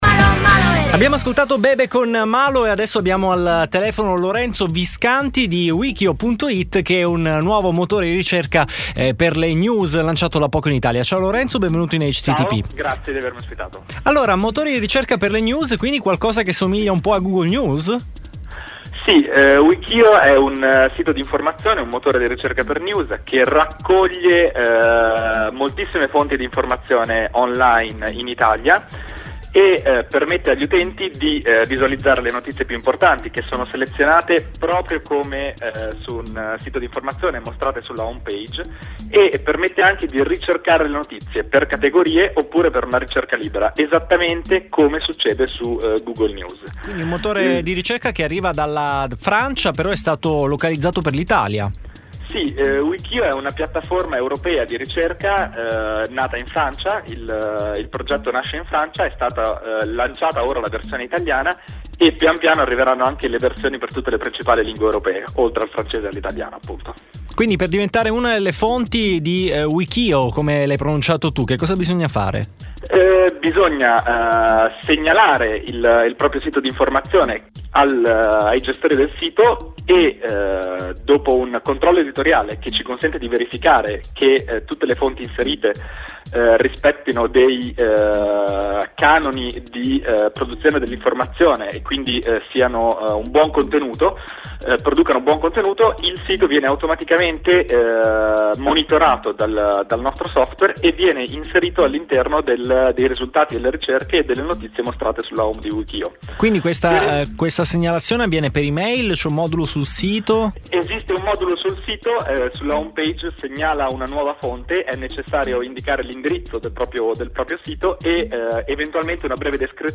Intervista citata